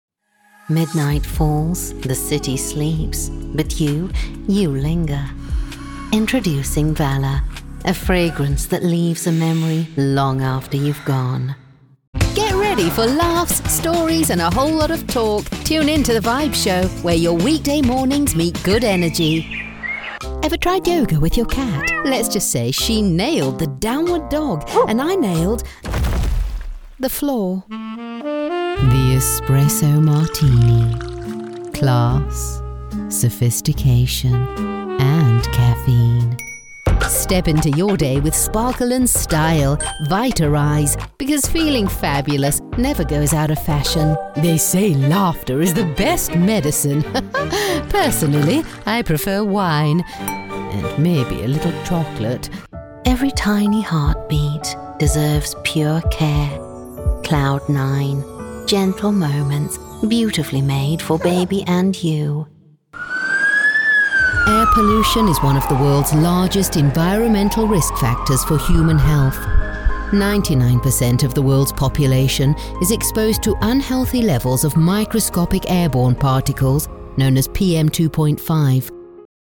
Anglais (britannique)
Anglais (sud-africain)
Calme
Amical
Naturel